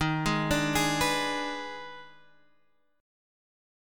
EbM7sus4#5 Chord